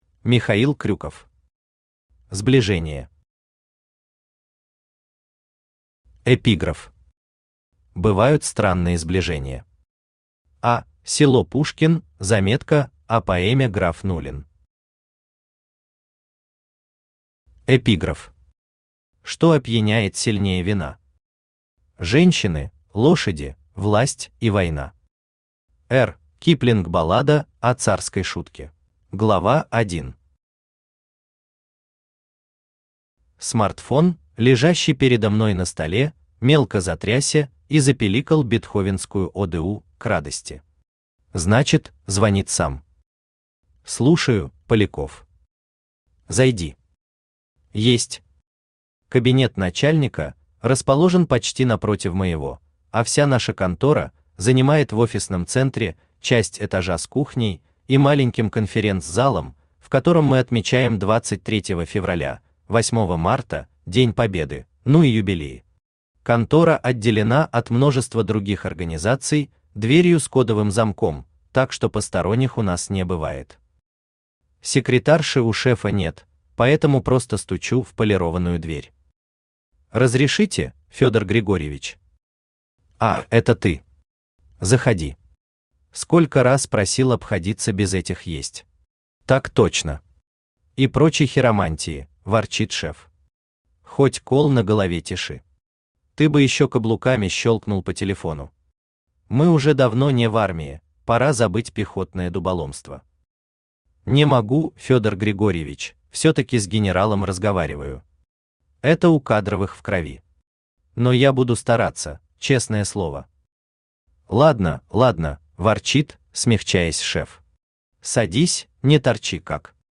Аудиокнига Сближения | Библиотека аудиокниг
Aудиокнига Сближения Автор Михаил Крюков Читает аудиокнигу Авточтец ЛитРес.